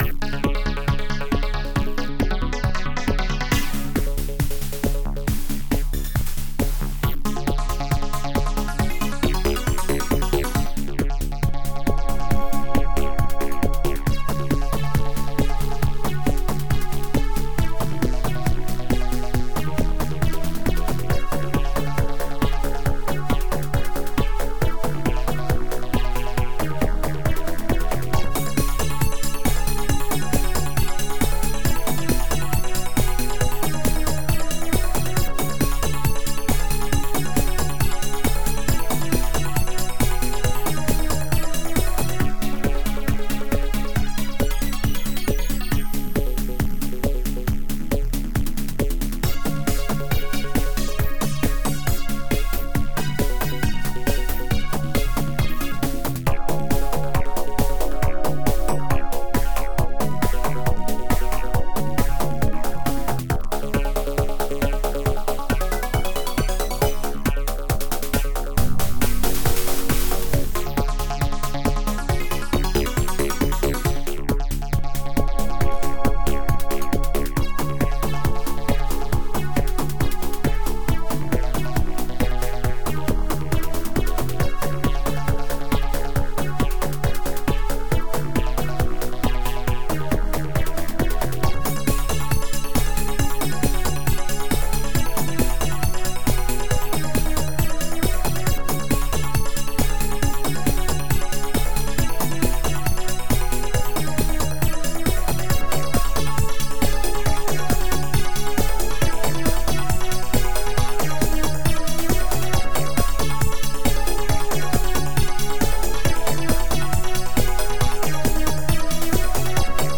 HSC AdLib Composer